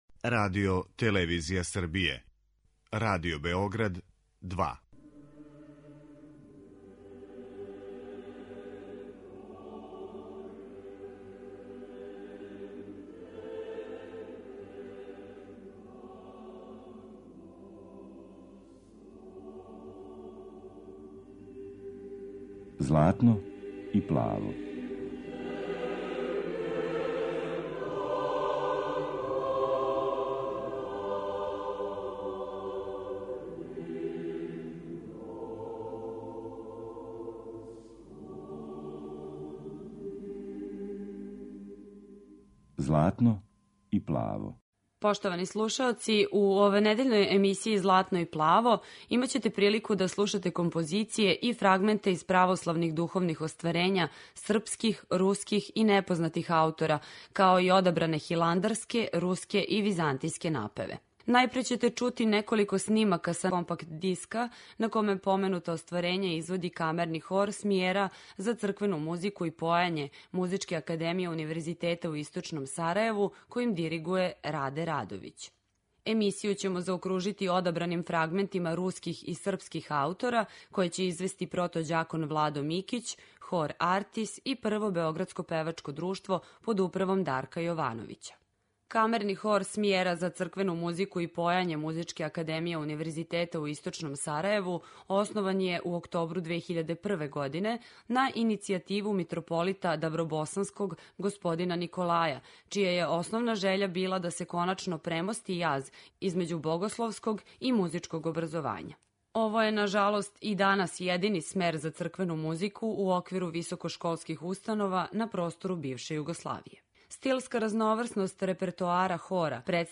Емисија је посвећена православној духовној музици.